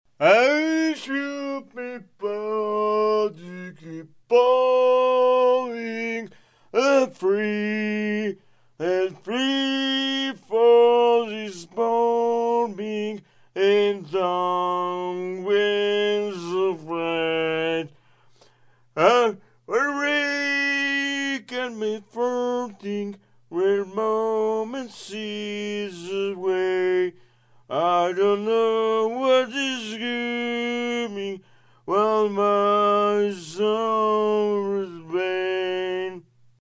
Звуки пьяного человека